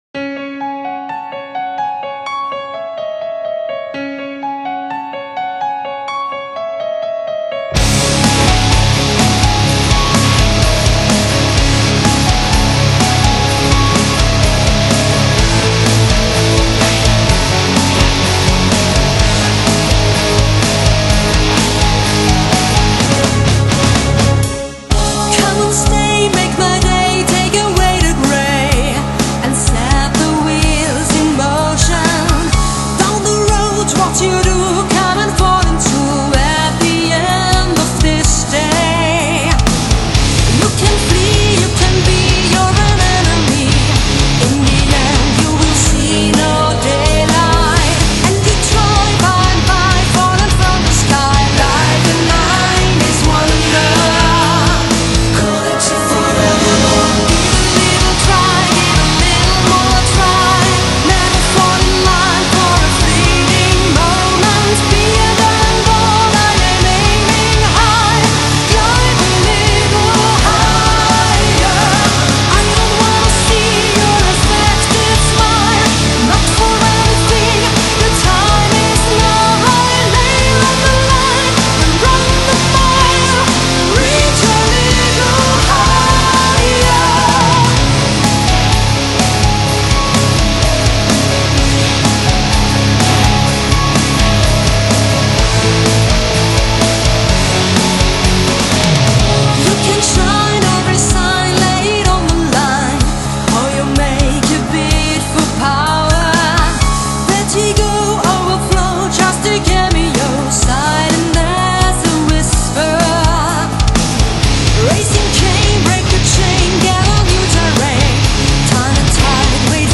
奧地利首席美聲金屬樂團
夢幻般天使嗓音譜出世紀之作